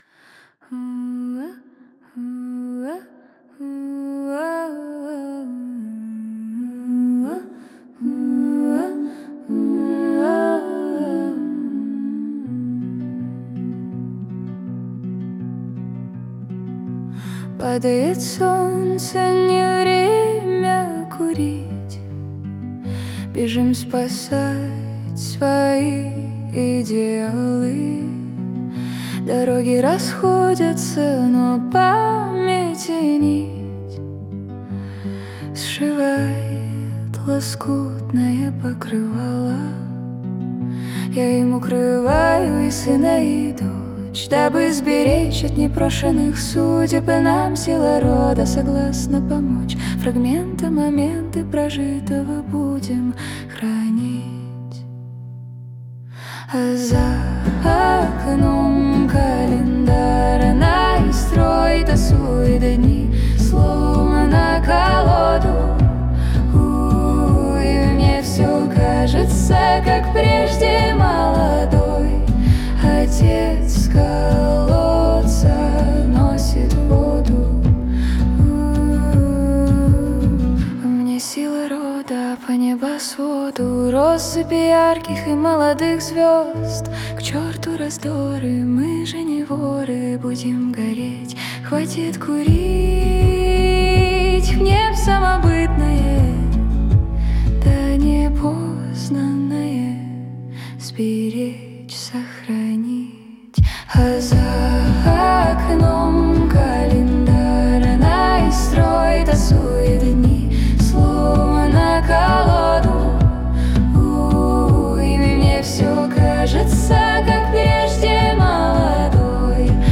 Чистый фолк.